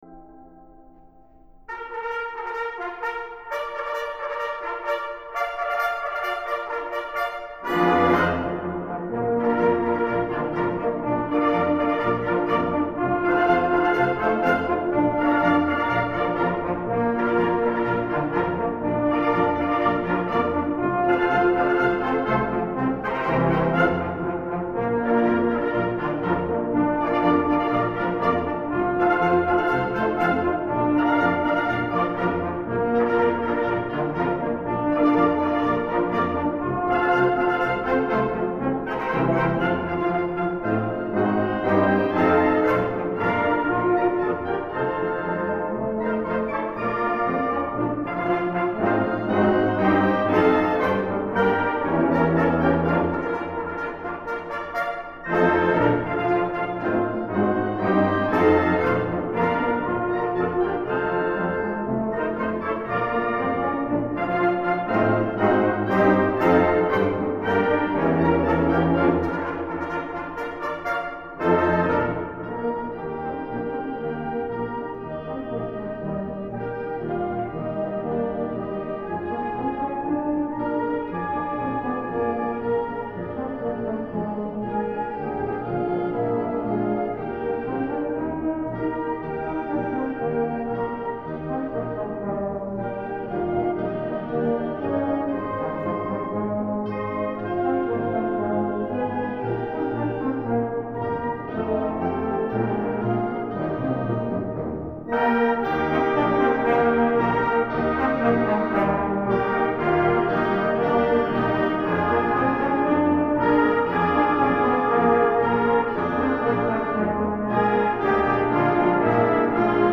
MILITARY ESCORT - Harold Bennett Recorded August 28, 2012 at the Cibola High School Performing Arts Center
Military Escort - Bennett - Rio Rancho Symphonic Band.mp3